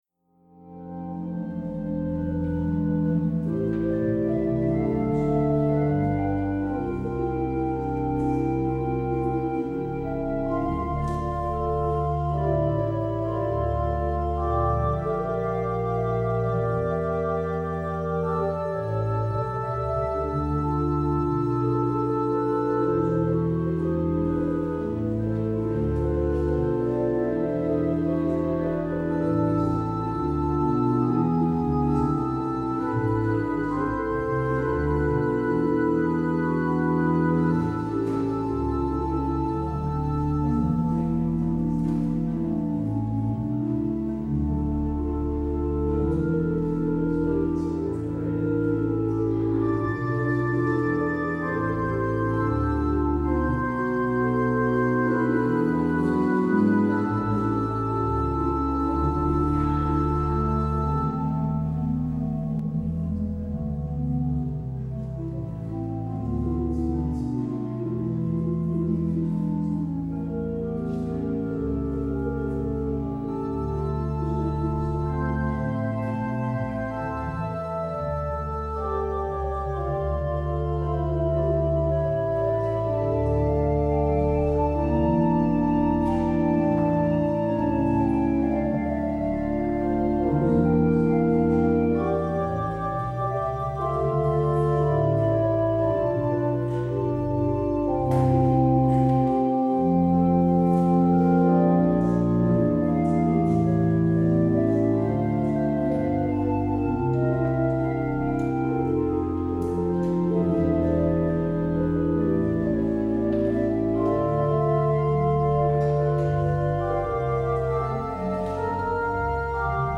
 Luister deze kerkdienst hier terug: Alle-Dag-Kerk 17 september 2024 Alle-Dag-Kerk https